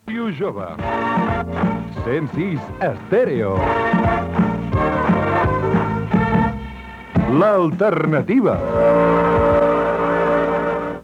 Identificació en català de l'emissora.
FM